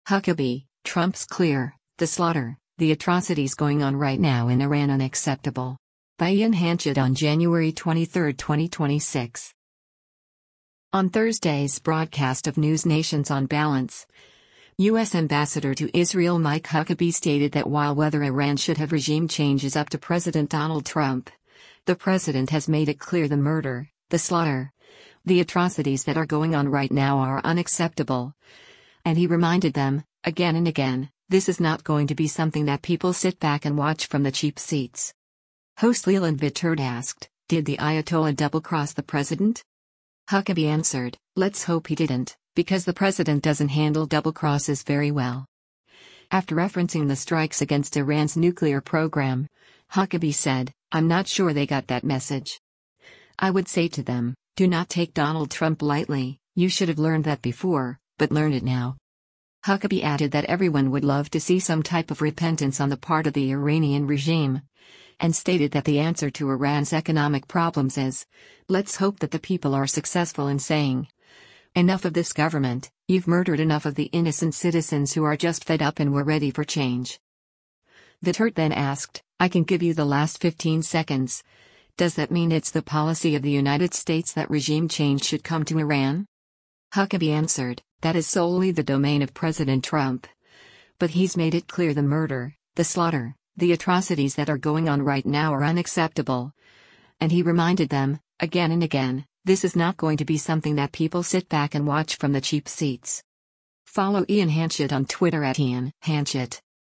On Thursday’s broadcast of NewsNation’s “On Balance,” U.S. Ambassador to Israel Mike Huckabee stated that while whether Iran should have regime change is up to President Donald Trump, the President has “made it clear the murder, the slaughter, the atrocities that are going on right now are unacceptable, and he reminded them, again and again, this is not going to be something that people sit back and watch from the cheap seats.”
Host Leland Vittert asked, “Did the ayatollah double-cross the President?”